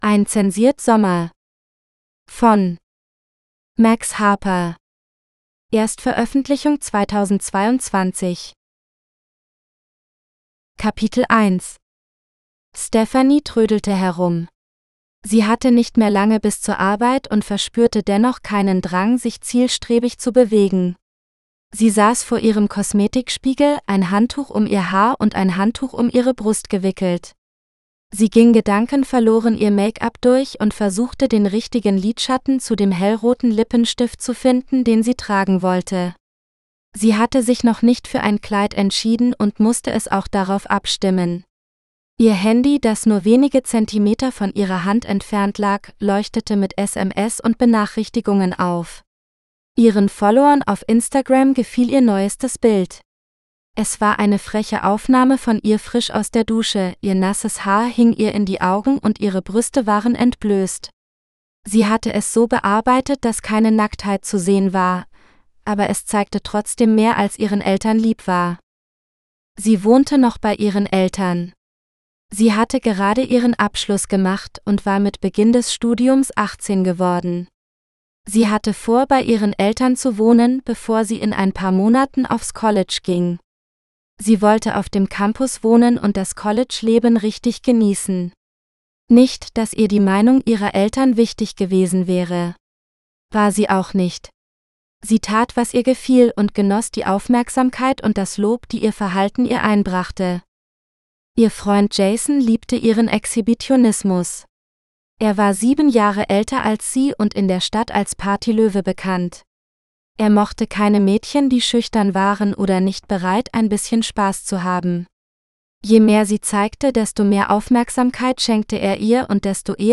A Redacted Summer GERMAN (AUDIOBOOK – female): $US5.75